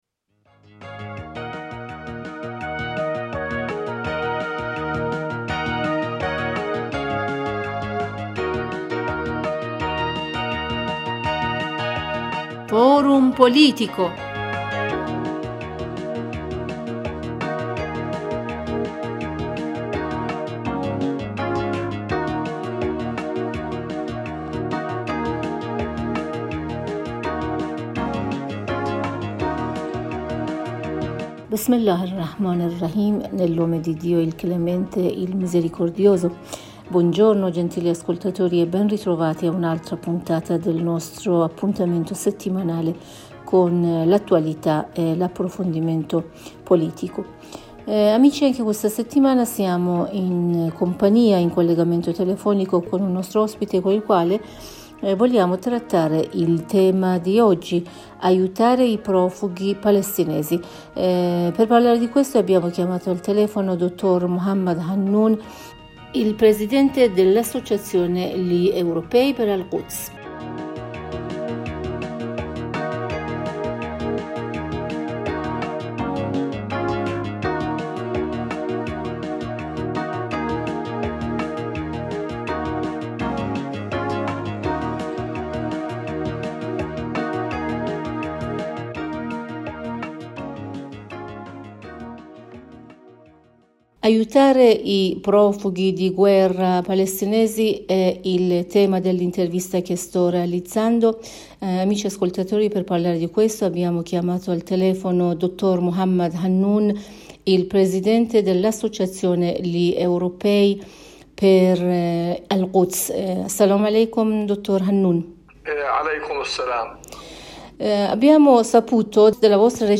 Per ascoltare la prima parte dell’intervista cliccare qui sopra ↑